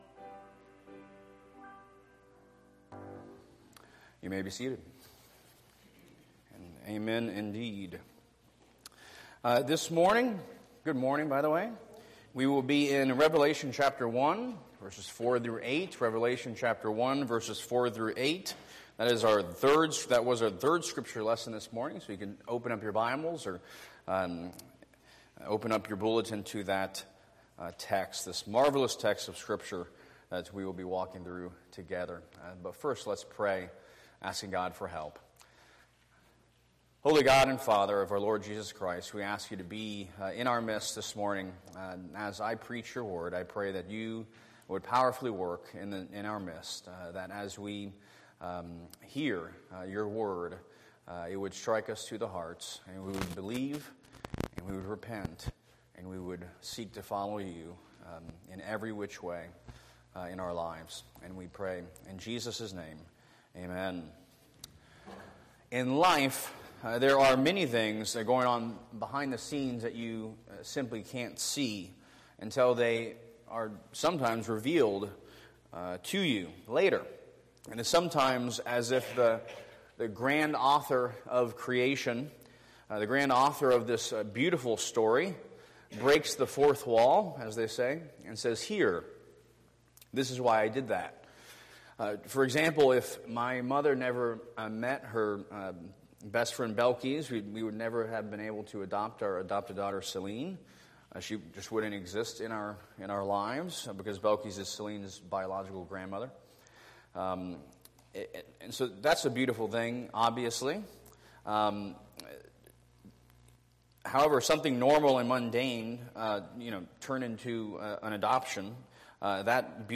Recent sermons from the pulpit of Christ Central Prebyterian Church in Tampa, FL